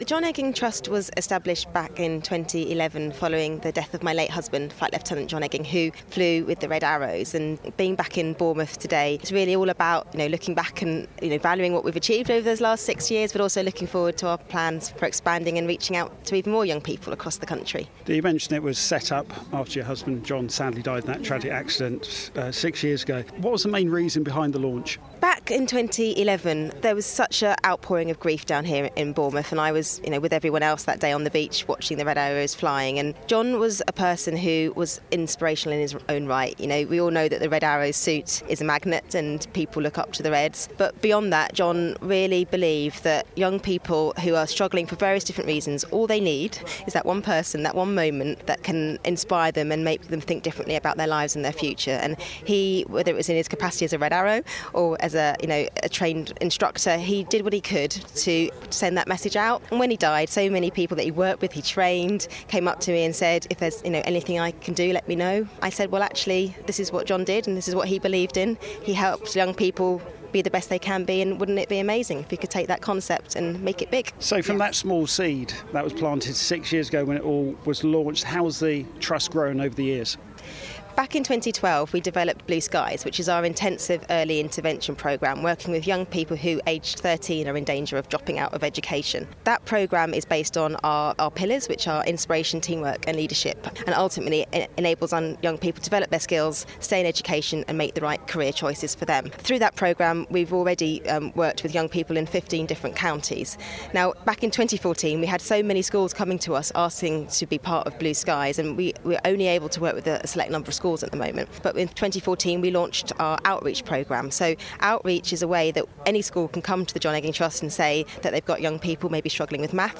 at this year’s Air Festival